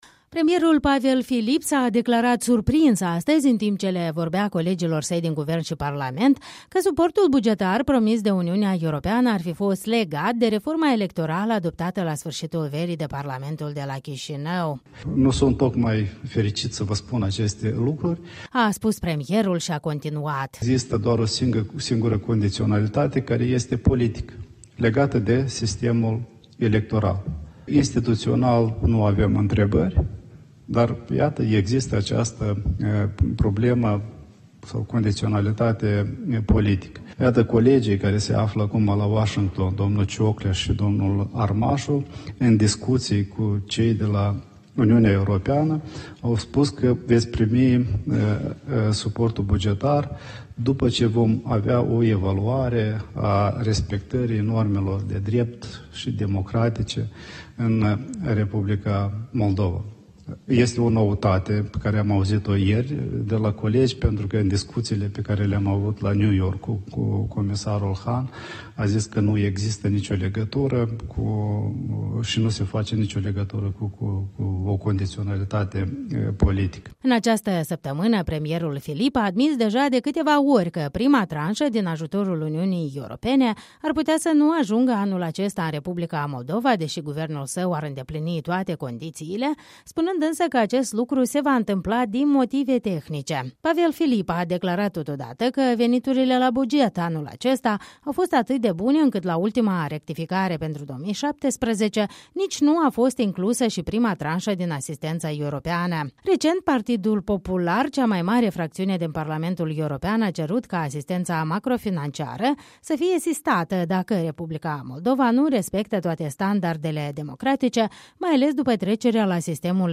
La ședința comună a parlamentului și cabinetului de miniștri, șeful executivului s-a arătat surprins de condiționarea politică a sprijinului financiar UE.